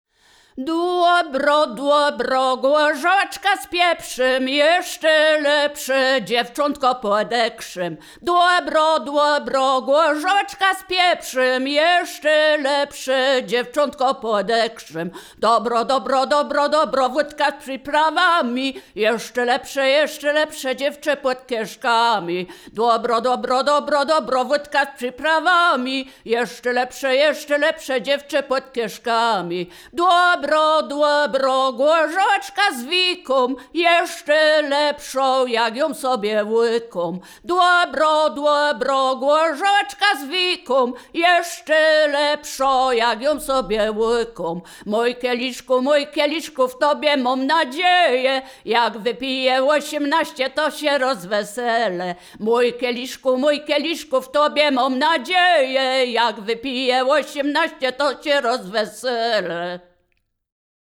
województwo wielkopolskie, powiat gostyński, gmina Krobia, wieś Posadowo
miłosne żartobliwe przyśpiewki